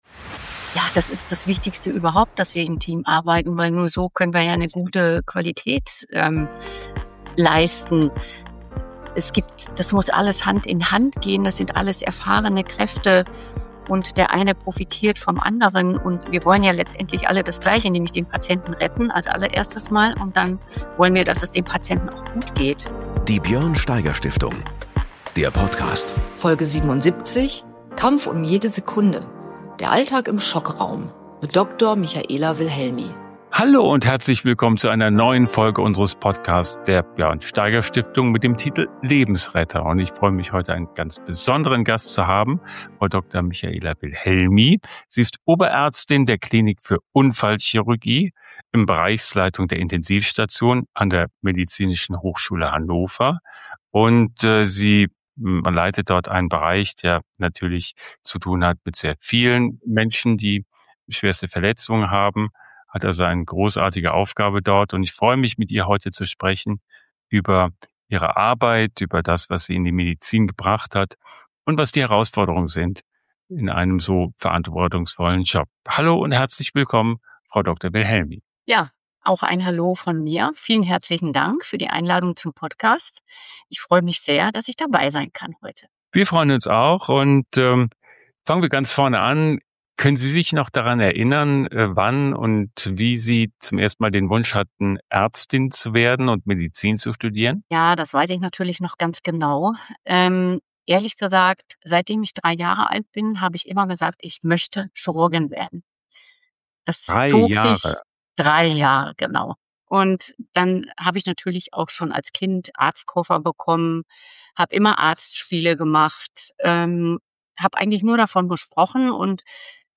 Ein ehrliches Gespräch über Belastung, Verantwortung, medizinischen Fortschritt – und über das, was Kraft gibt, weiterzumachen.